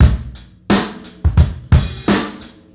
little drag 88bpm.wav